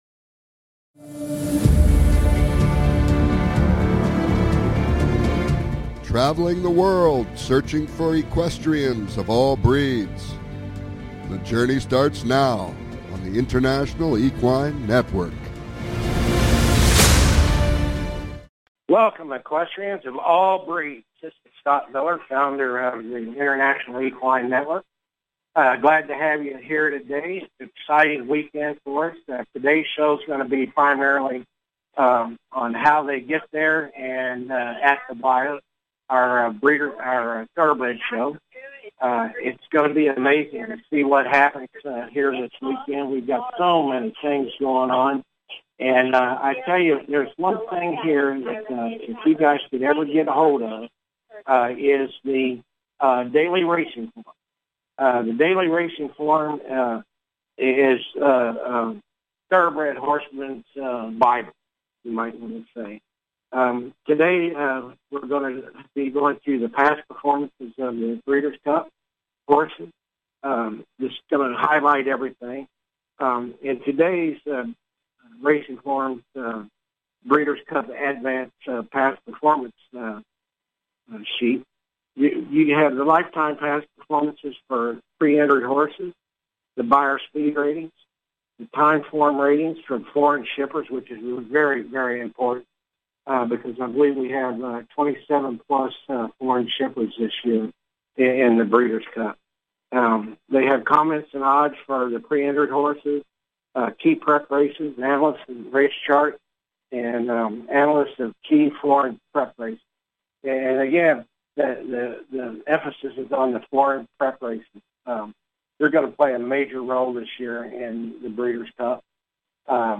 Talk Show Episode
This show will bring you the inside scoop! Calls-ins are encouraged!